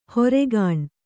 ♪ horegāṇ